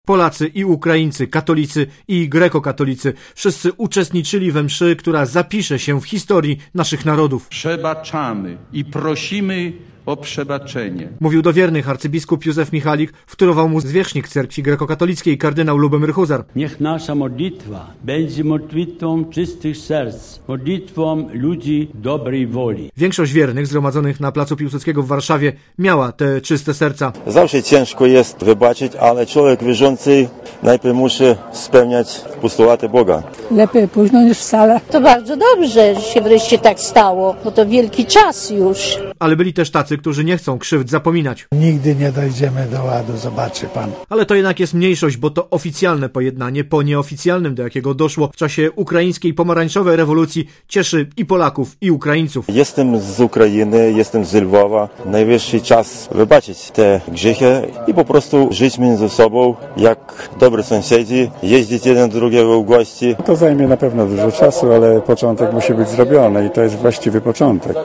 Relacja
Msza święta w Warszawie zakończyła III Krajowy Kongres Eucharystyczny.